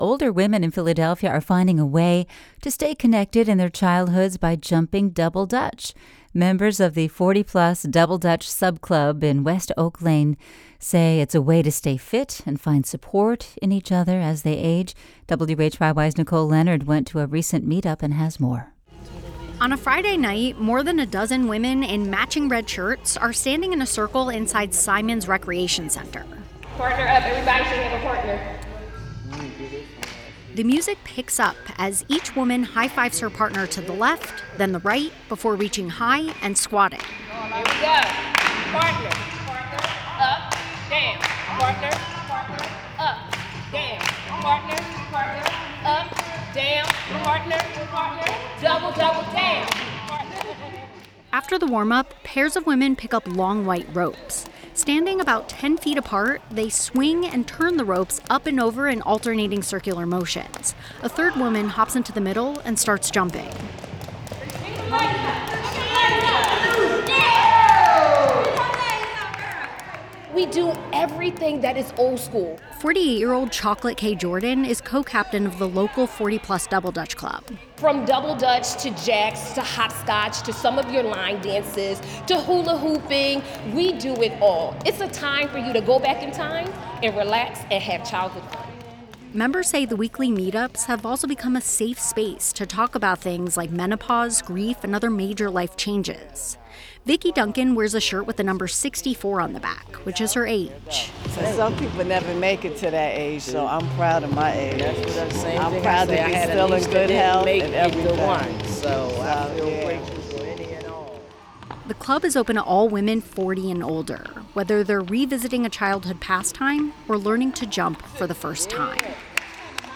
Eighth District City Councilwoman called NewsWorks on Thursday morning from Charlotte, NC, where she is among many City Hall Democrats attending their party’s national convention.